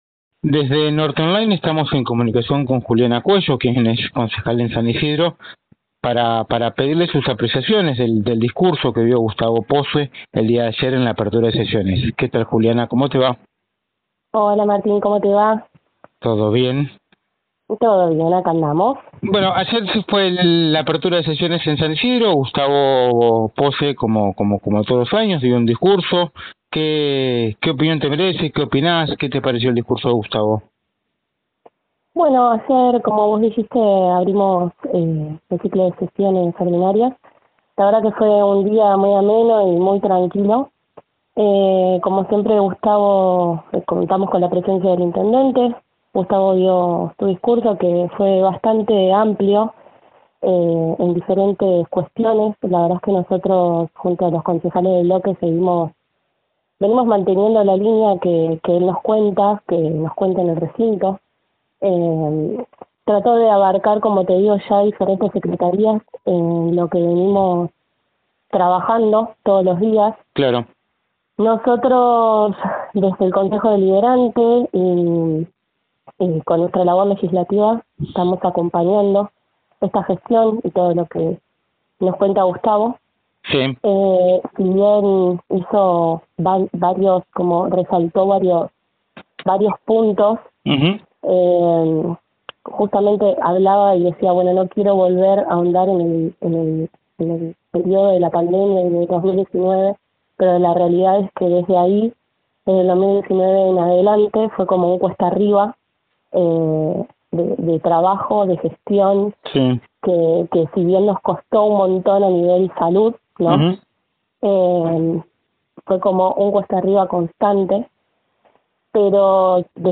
La concejala de Juntos por el Cambio en San Isidro habló con NorteOnline y mostró su conformidad tras la apertura de sesiones.